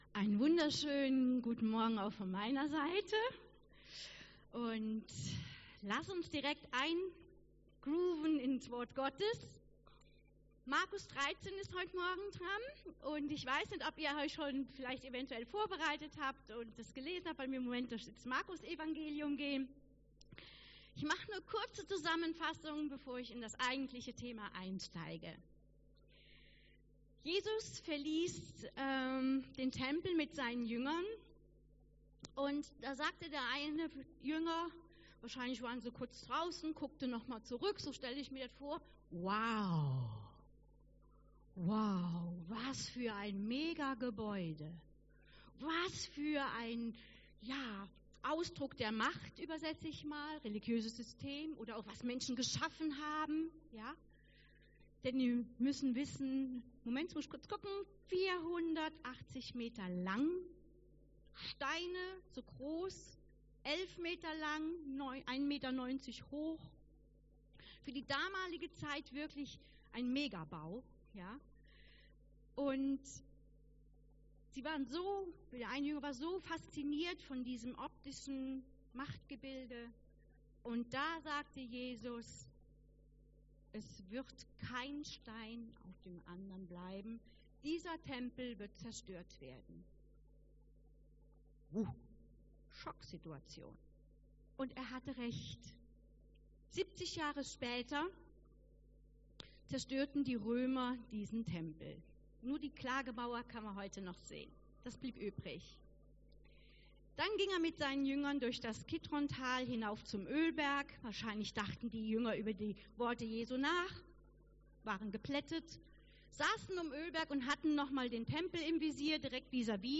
Predigt 11.07.2021